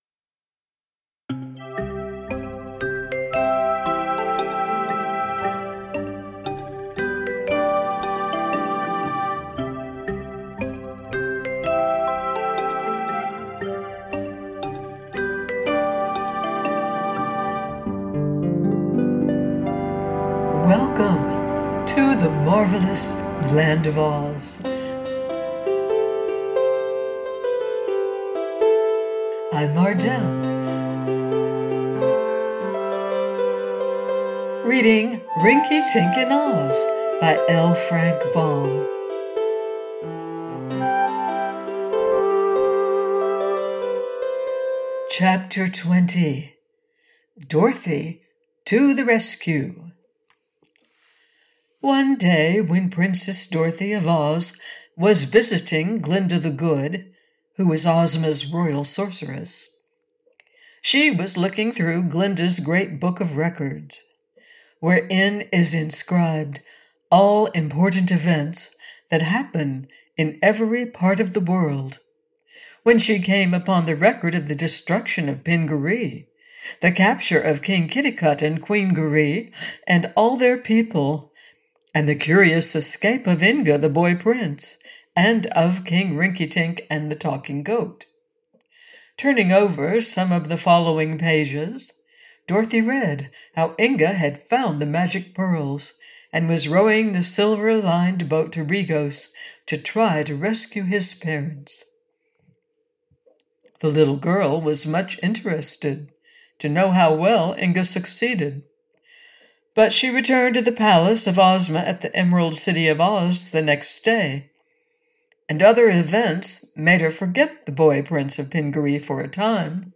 Rinkitink In Oz – by Frank L. Baum - AUDIOBOOK